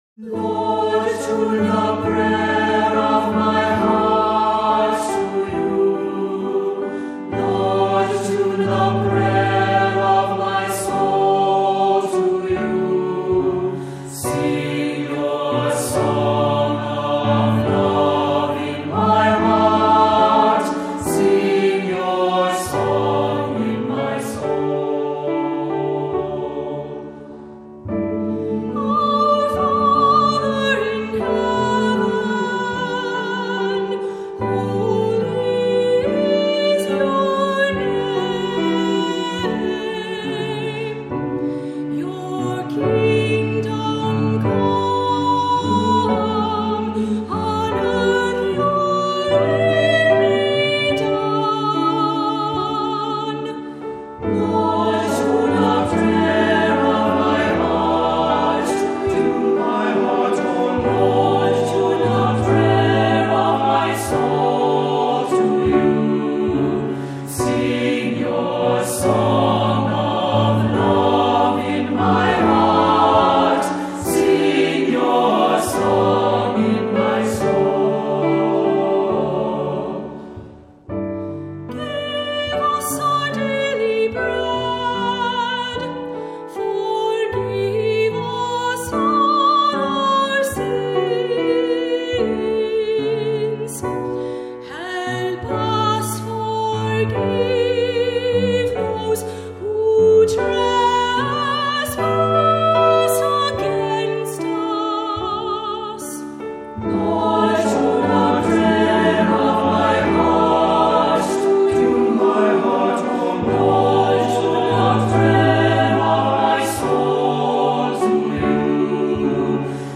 Voicing: SATB; cantor; assembly